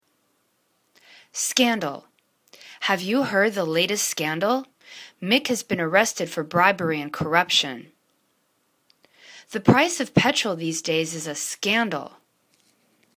scan.dal     /'skandl/    n